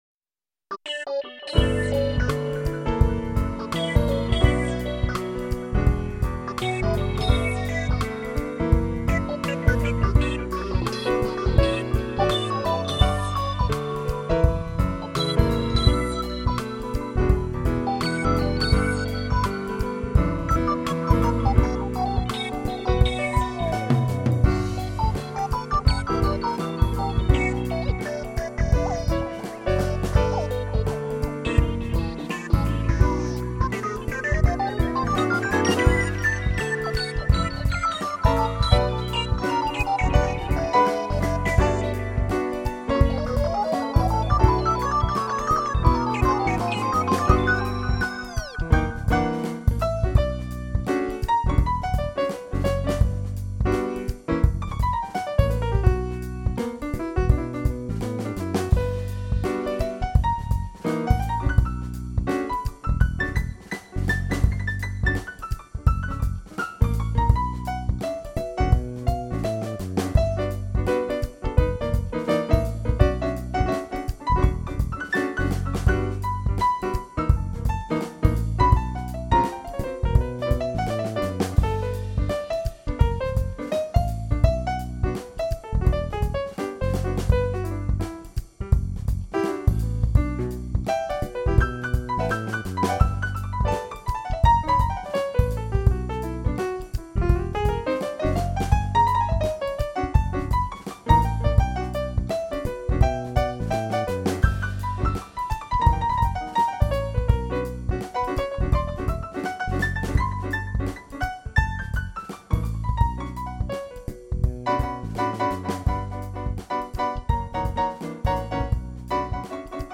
Manytone FreeZone KGP This is a grand piano which was sampled using three mics in 24 bit. The notes are sampled with full decay.
The piano itself is pretty bright, and has a lot of personality.